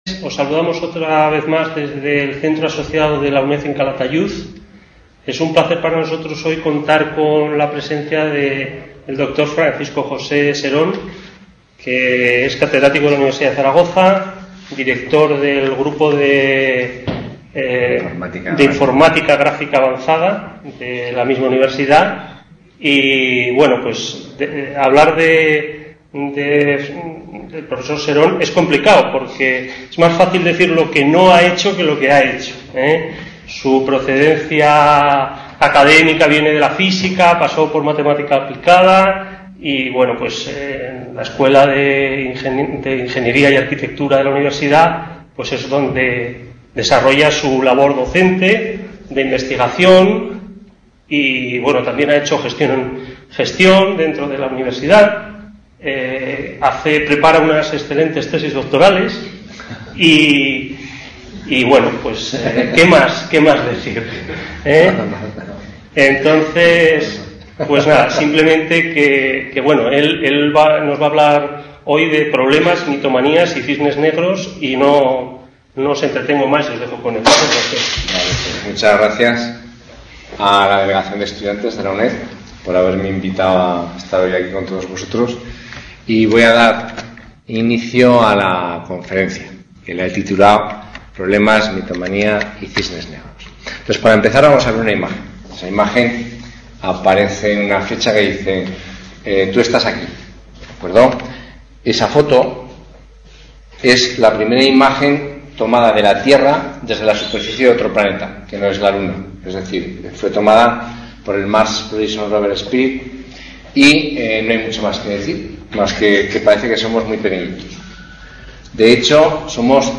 Conferencia: Problemas, mitomanía y cisnes negros | Repositorio Digital